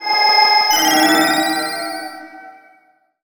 twinkle_glitter_dark_spell_02.wav